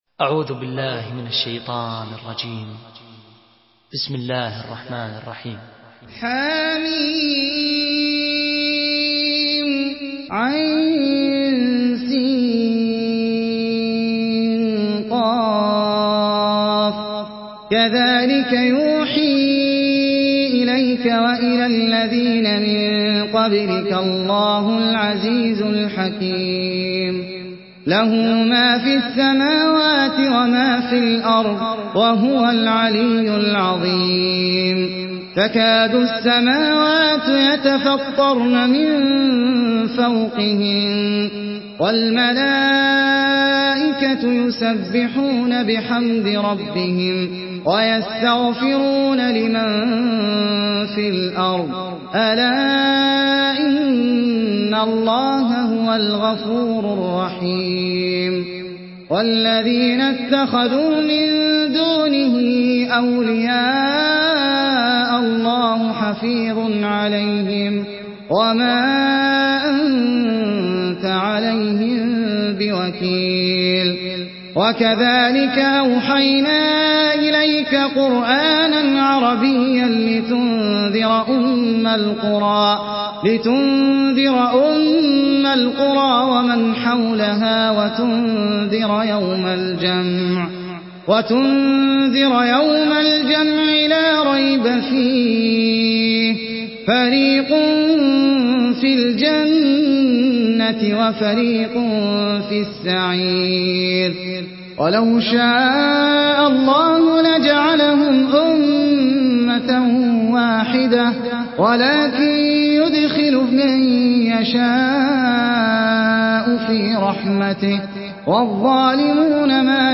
Surah Ash-Shura MP3 by Ahmed Al Ajmi in Hafs An Asim narration.
Murattal